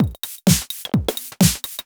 Electrohouse Loop 128 BPM (24).wav